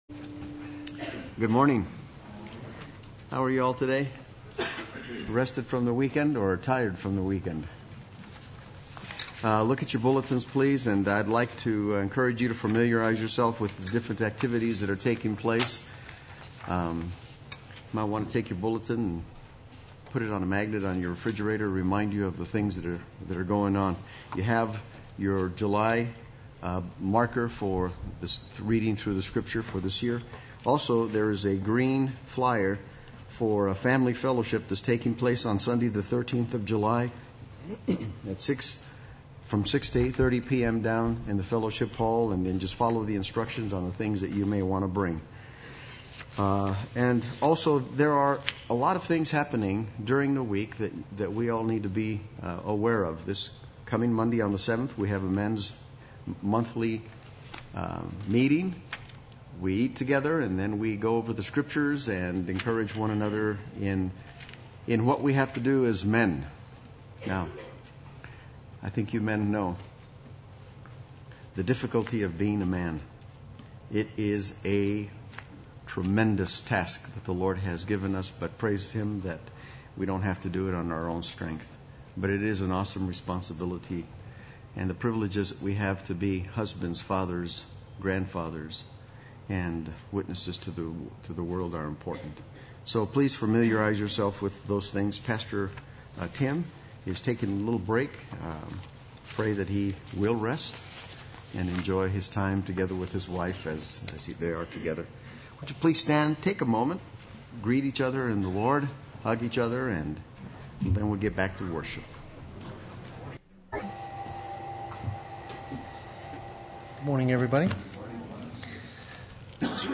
Play Sermon Get HCF Teaching Automatically.
Sunday Worship